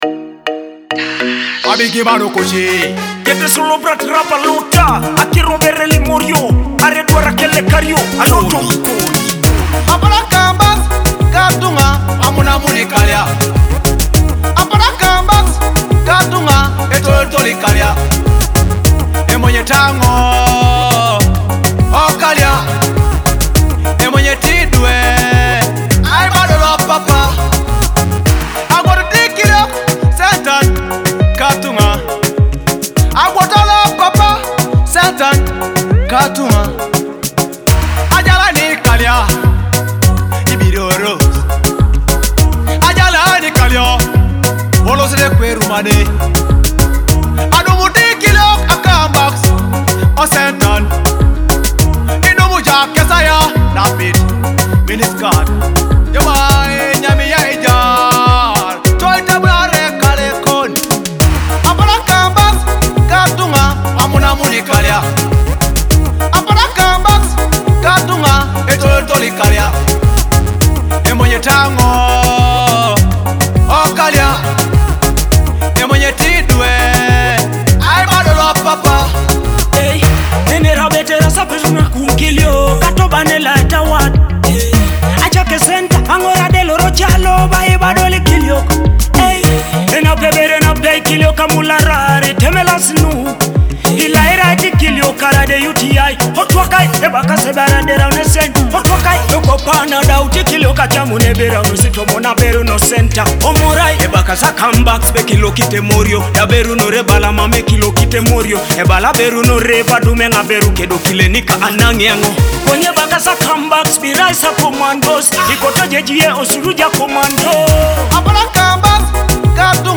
Blending Afrobeat and traditional Iteso sounds.
heartfelt Teso song